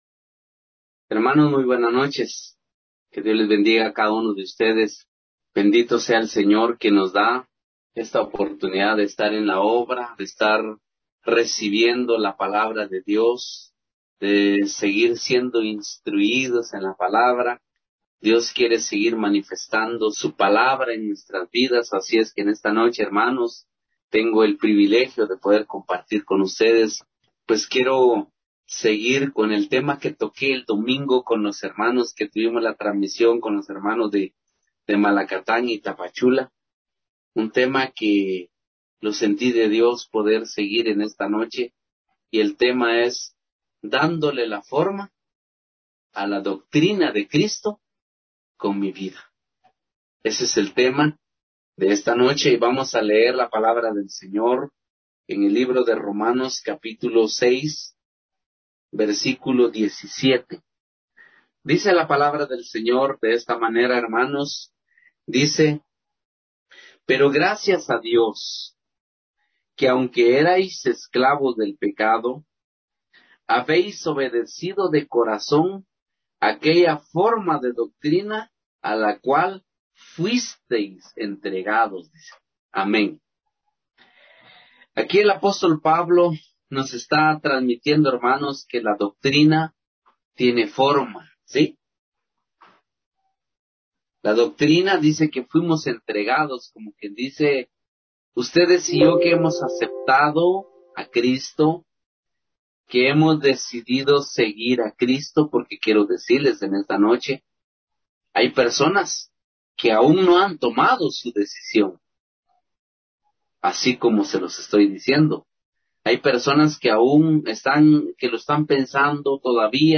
Audio de la Prédica